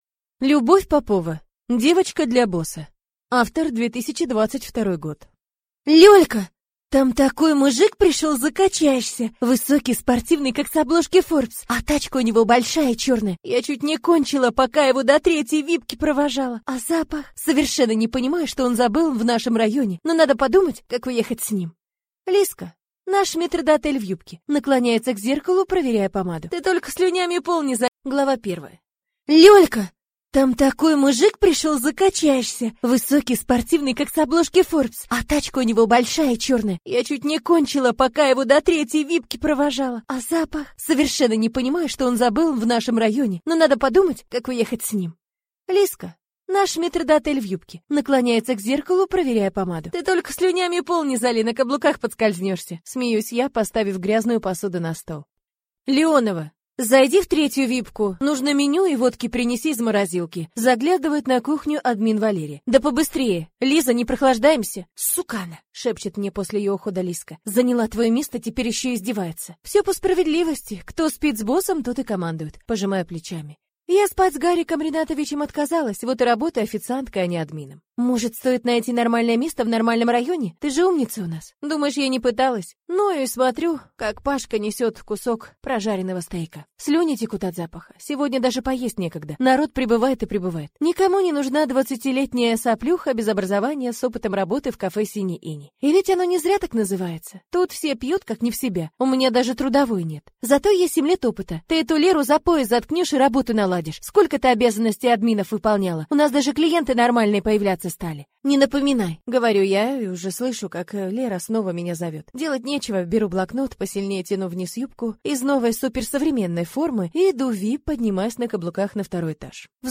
Аудиокнига Девочка для босса | Библиотека аудиокниг